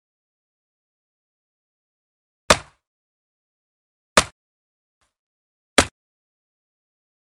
axe_wood_chop.ogg